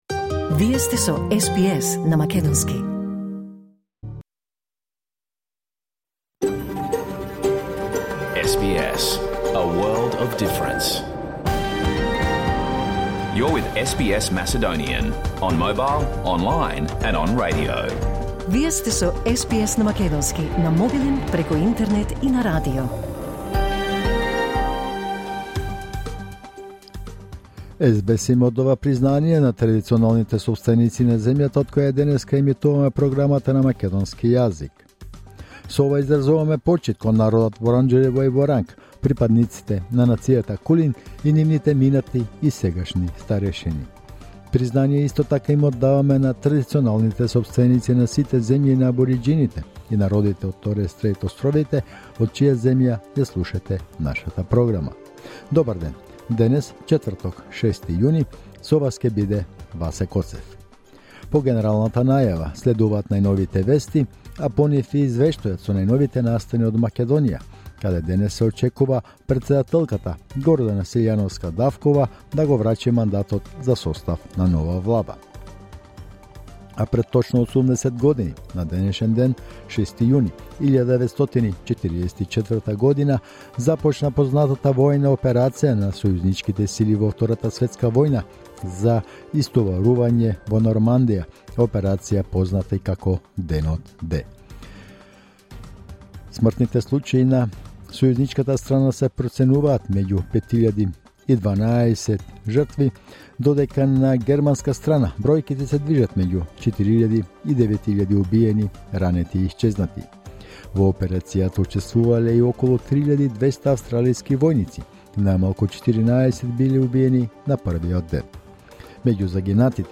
SBS Macedonian Пrogram Live on Air 6 June 2024